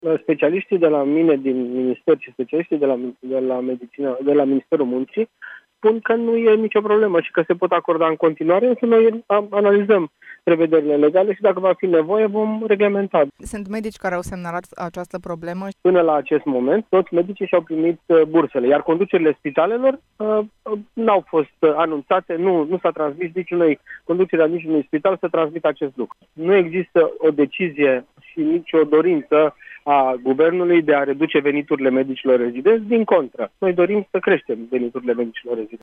În replică, ministrul Sănătăţii, Florian Bodog a declarat la Europa FM că specialiştii Ministerului Muncii şi cei din Ministerul Sănătăţii fac verificări, dar i-au dat asigurări că legea salarizării nu afectează bursele medicilor rezidenți.